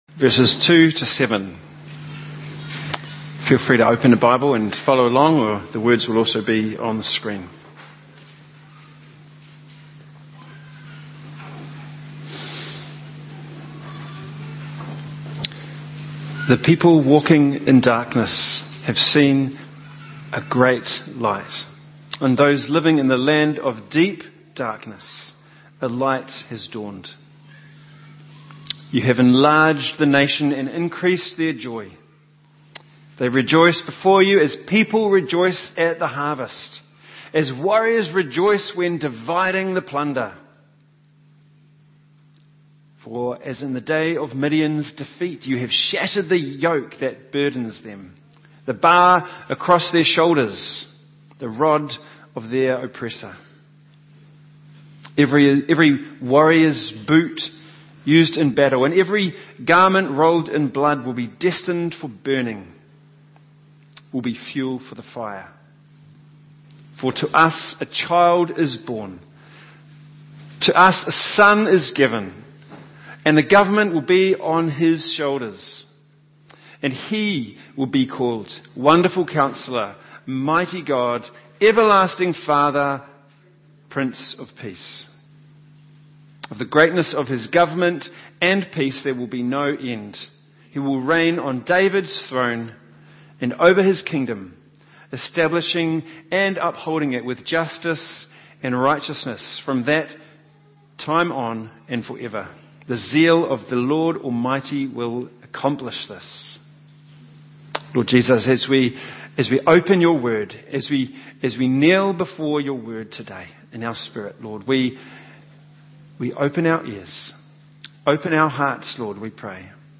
Passage: Isaiah 9:2-7 Service Type: Family